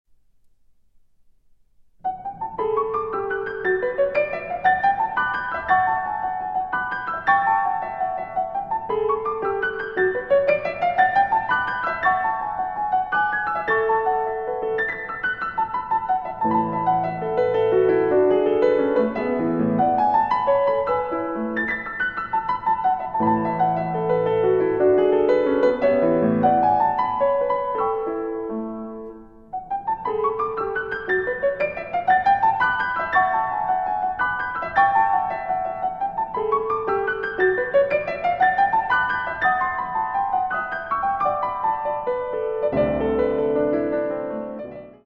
Allegretto liquido in modo pastorale